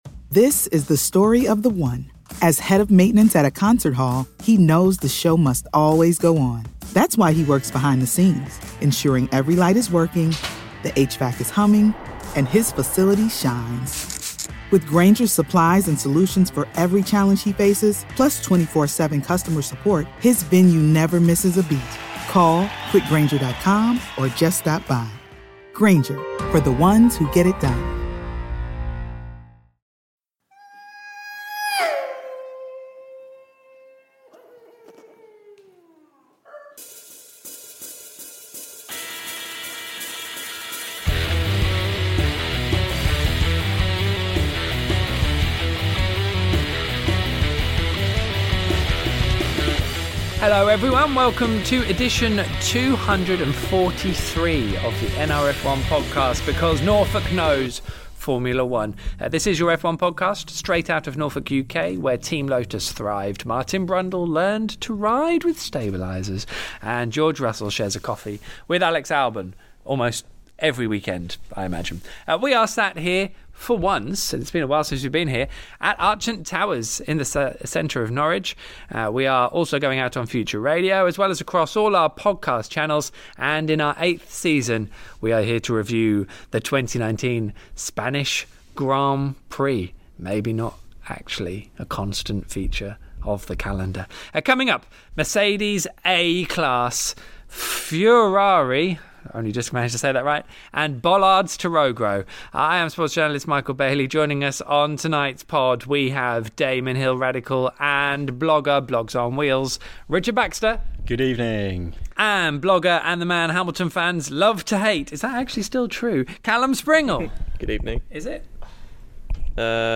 live on the phone